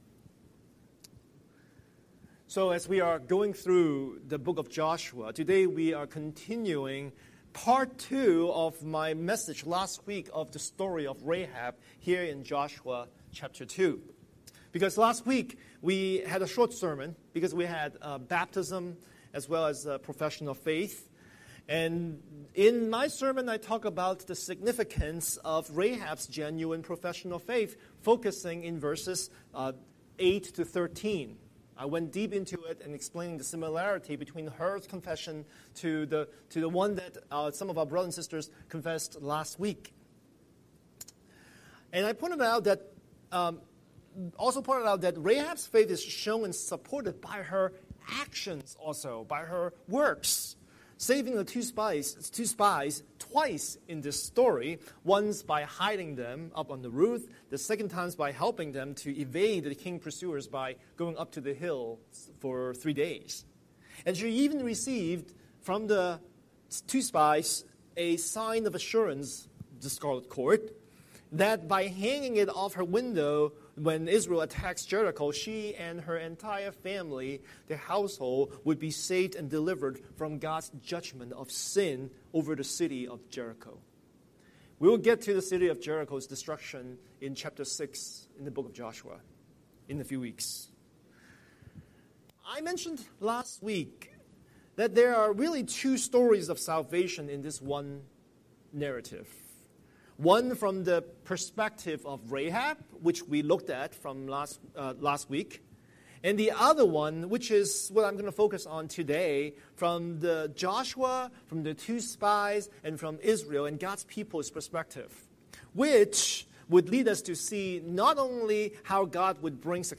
Scripture: Joshua 2:1–24 Series: Sunday Sermon